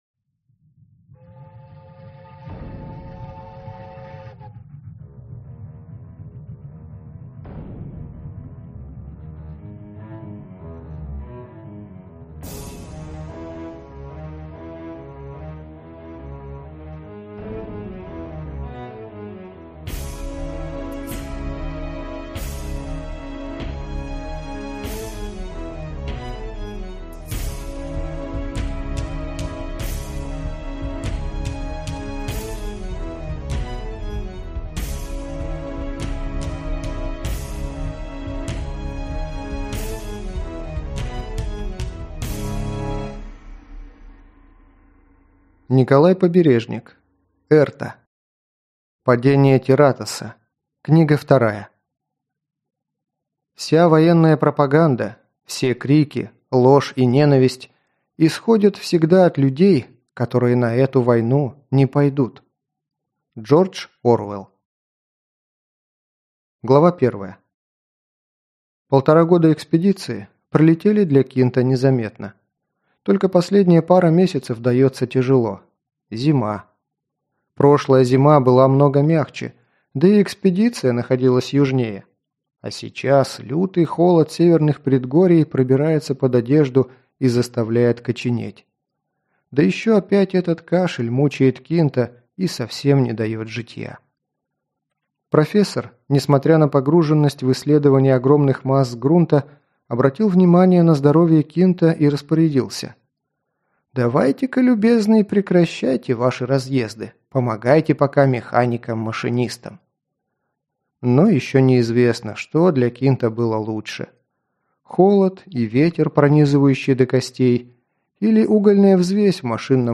Аудиокнига Падение Терратоса | Библиотека аудиокниг